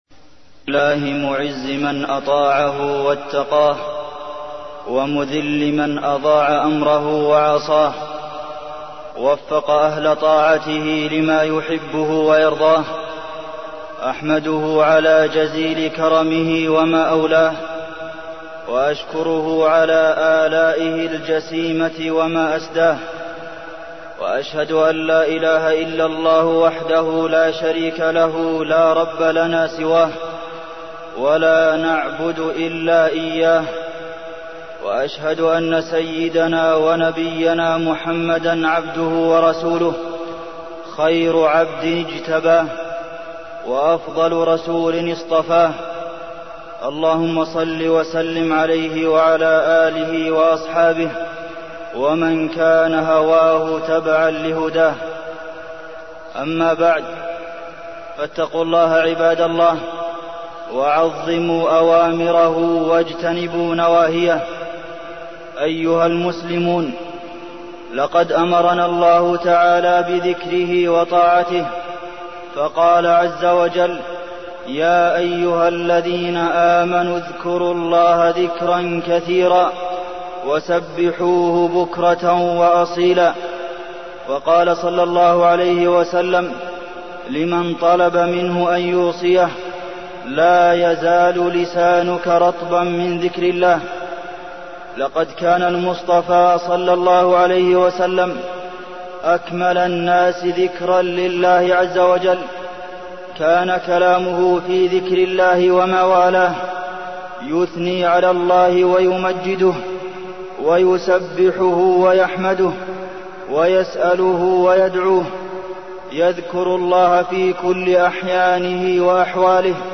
تاريخ النشر ٢٣ ربيع الأول ١٤١٩ هـ المكان: المسجد النبوي الشيخ: فضيلة الشيخ د. عبدالمحسن بن محمد القاسم فضيلة الشيخ د. عبدالمحسن بن محمد القاسم ذكر الله عز وجل